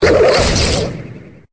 Cri de Noacier dans Pokémon Épée et Bouclier.